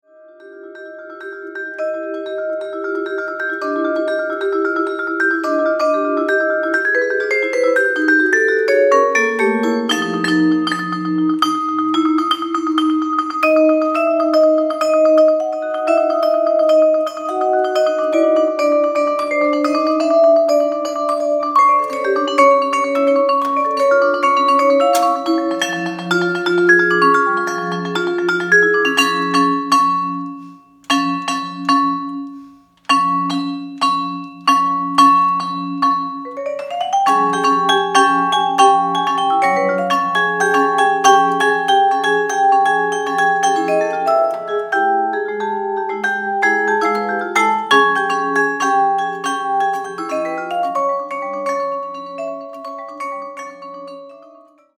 Genre: Solo 4-Mallet VIbraphone
Vibraphone [3-octave]